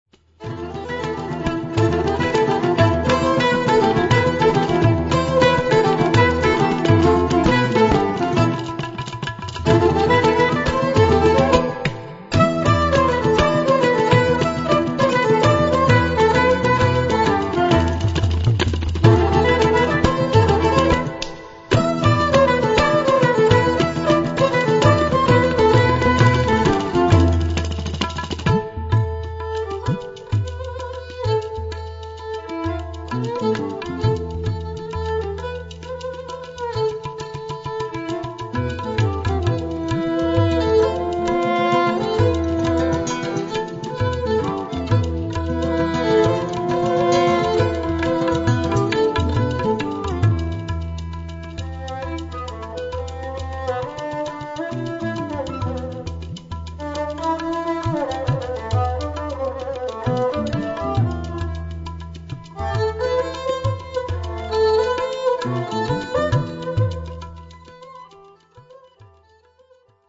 guitar
kaval, soprano sax, bansuri, whistle, sansa, guitar
kanjira, ghatam, morsing, small percussion
tabla, kalimba, small percuission
gadulka, kalimba, mbira, ektar